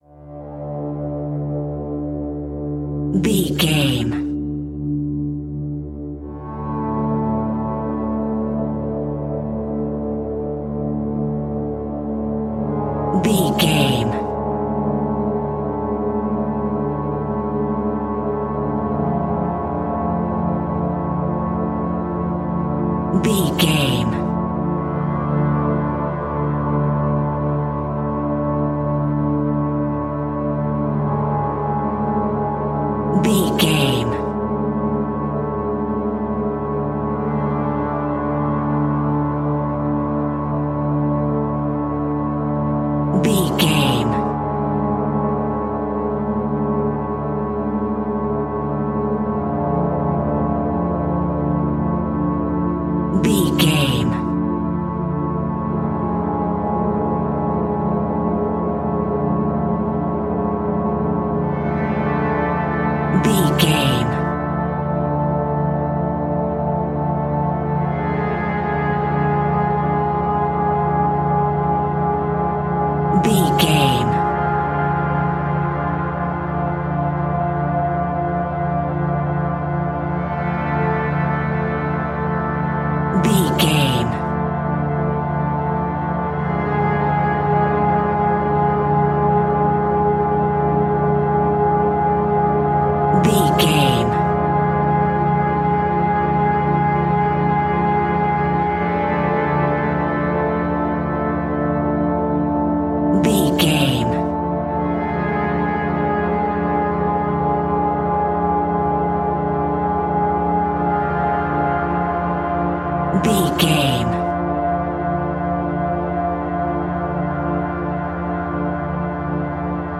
In-crescendo
Thriller
Aeolian/Minor
scary
ominous
haunting
eerie
creepy
horror music
Horror Pads
horror piano
Horror Synths